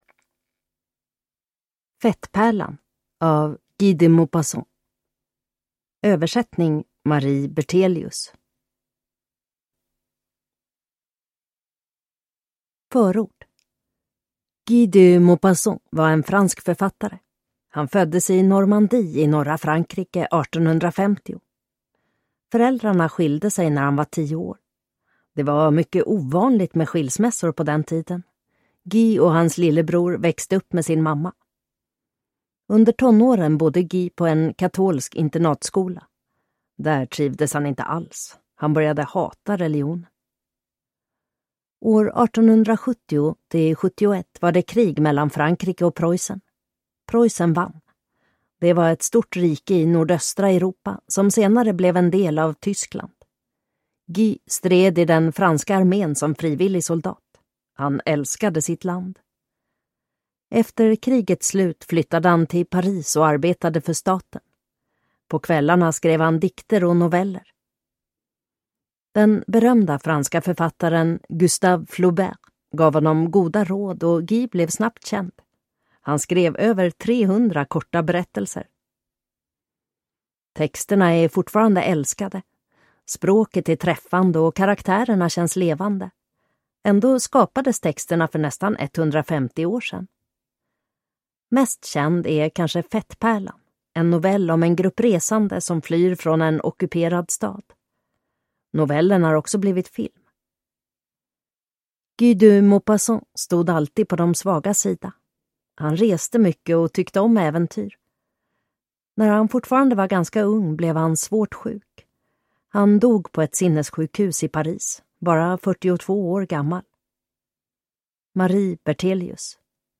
Fettpärlan (lättläst) – Ljudbok